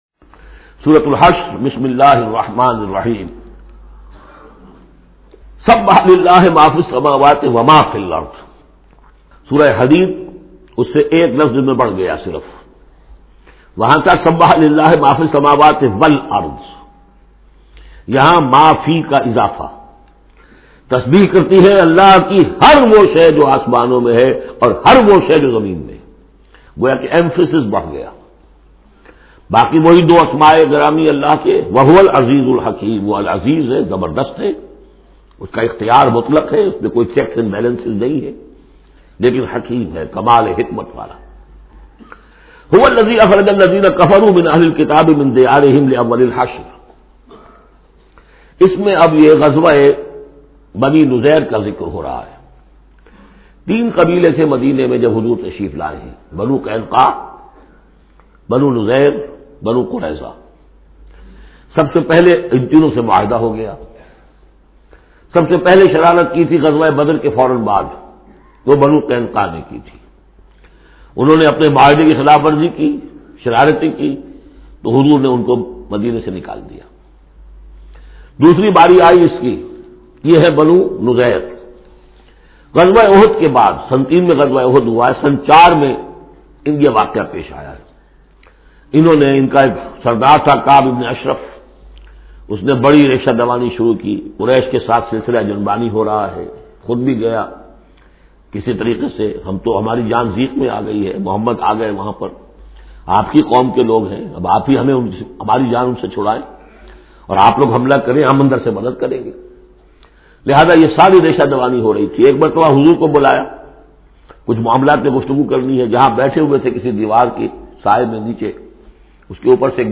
Surah Hashr Urdu Tafseer by Dr Israr Ahmed
Surah Hashr is 59 chapter of Holy Quran. Listen online mp3 urdu tafseer of Surah Hashr in the voice of Dr Israr Ahmed.